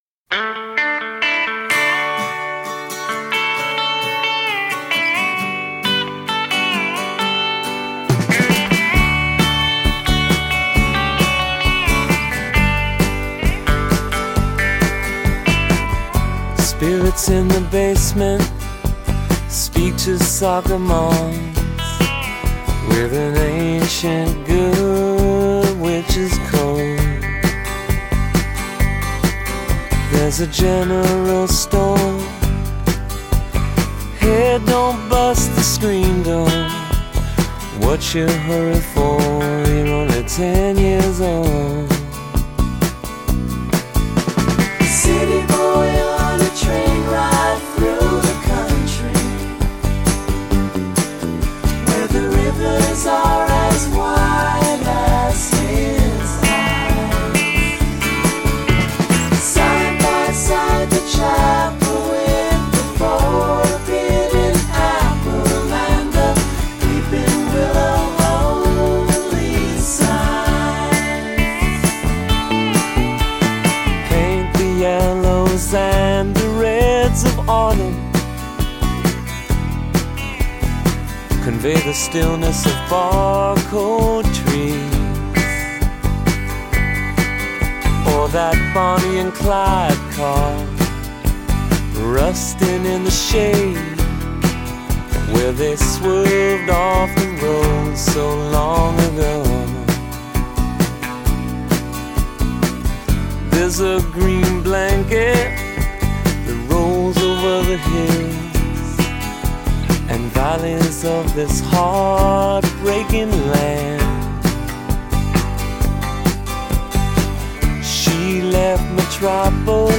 blending easy-going early 70’s pop sounds since 2002.
But this is just his “CSN song”.